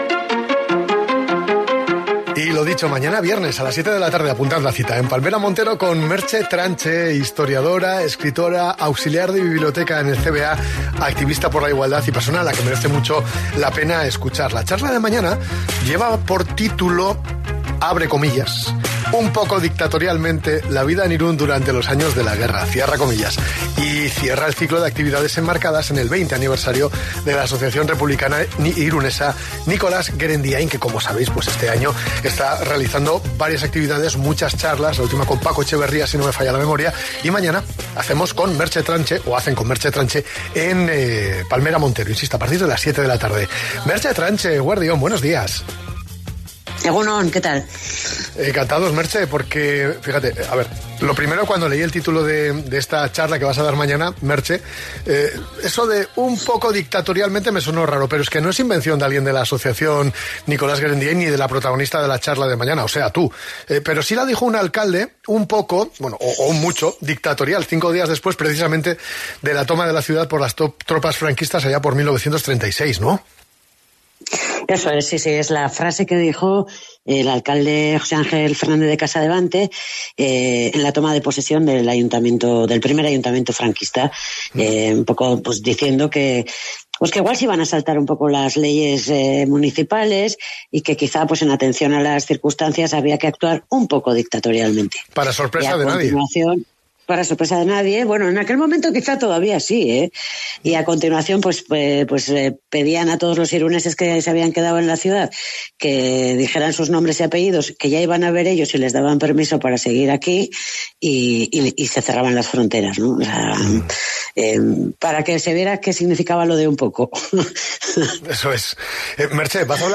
Bideoa eta argazkiak Deskargatu kartela 20. urteurreneko elkarrizketak Elkarrizketa Radio Irunen